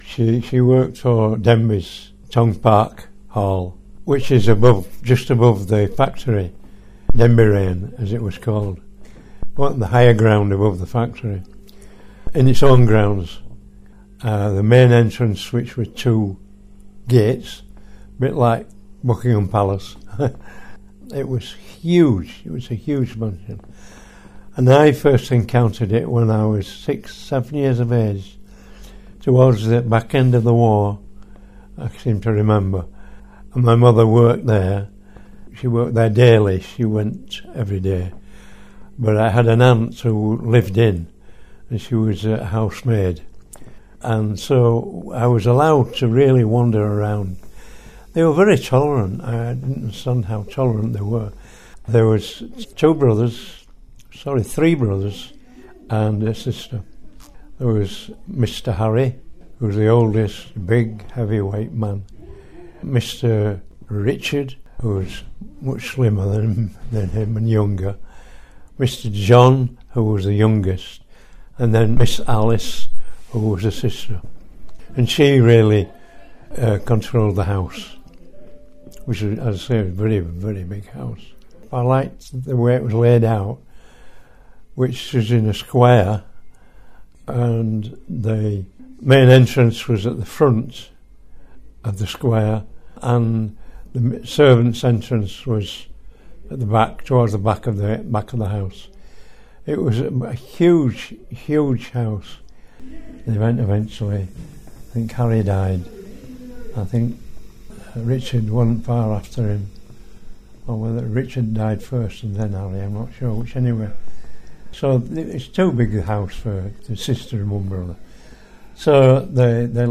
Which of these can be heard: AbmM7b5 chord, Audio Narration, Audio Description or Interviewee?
Interviewee